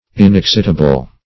Inexcitable \In`ex*cit"a*ble\, a. [L. inexcitabilis from which